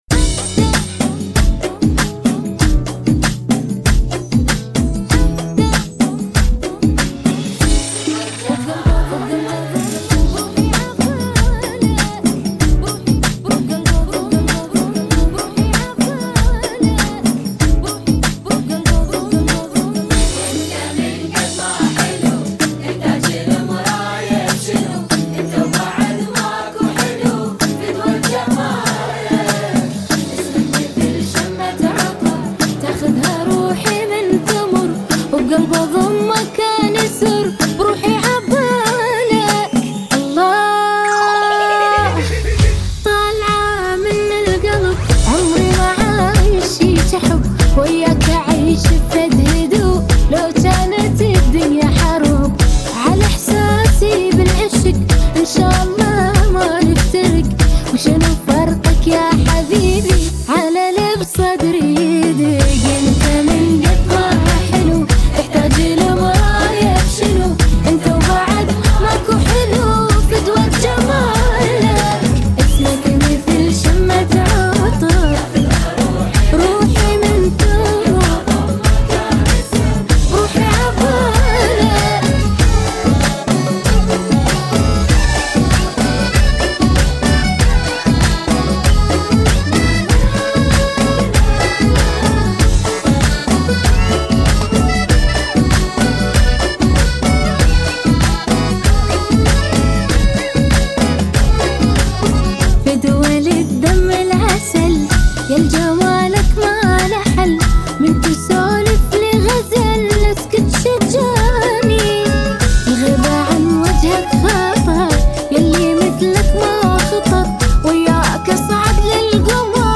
[ 96 bpm ] 2022